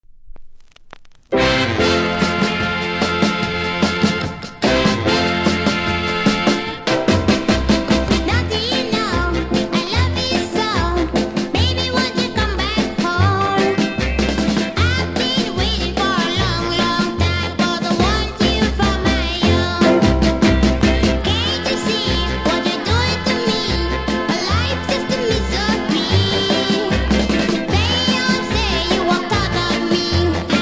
キズ多めで見た目悪いですが音は良好なので試聴で確認下さい。